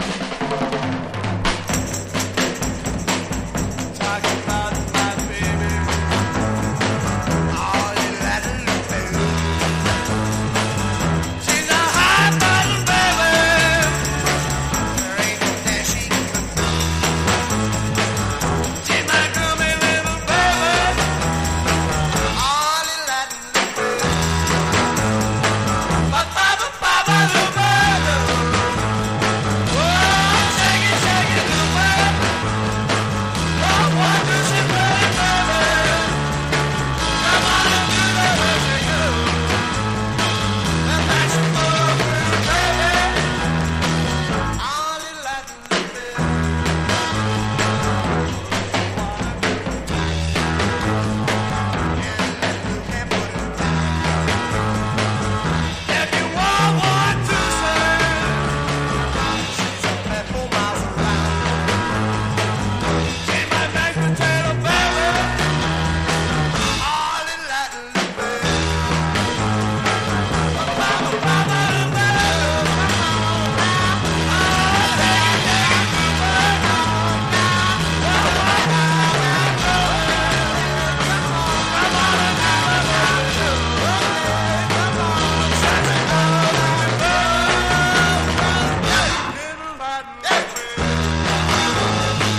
破壊力抜群の絶叫フラットロック